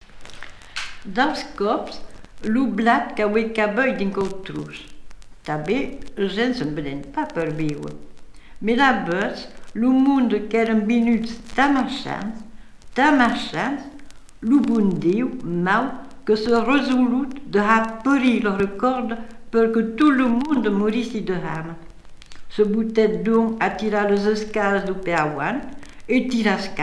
Catégorie Récit